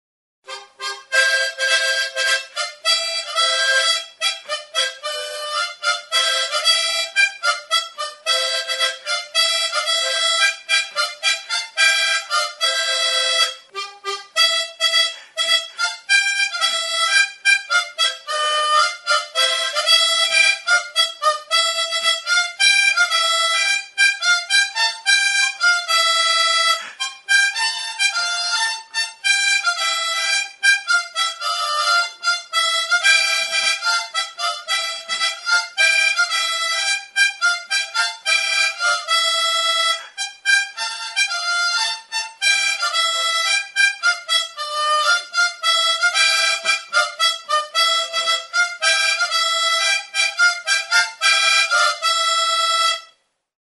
Enregistré avec cet instrument de musique.
AHO-SOINUA; EZPAINETAKO SOINUA; XOTIXE; HARMONIKA